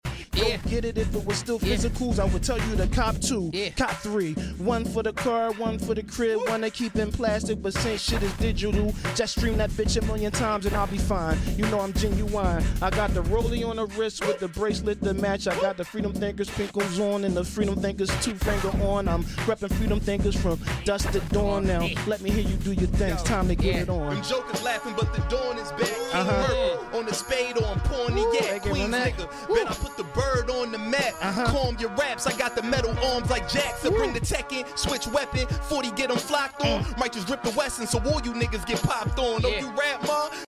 freestyling back to back